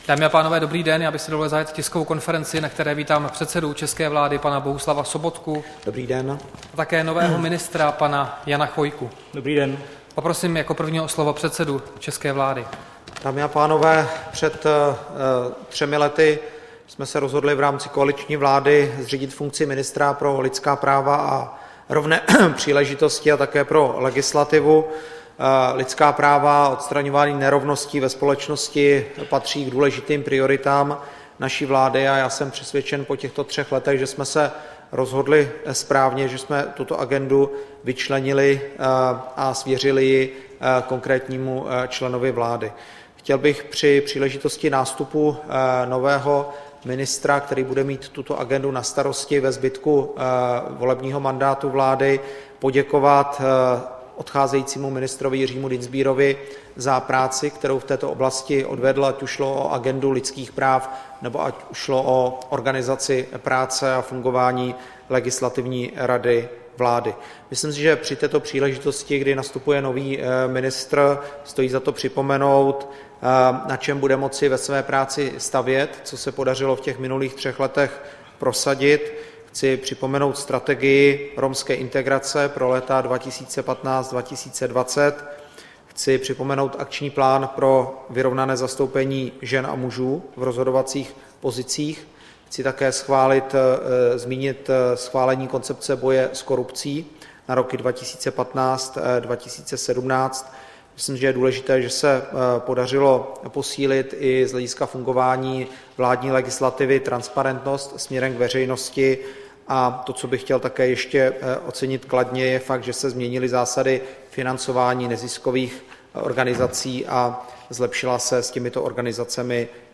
Tisková konference při uvedení do funkce ministra Jana Chvojky, 30. listopadu 2016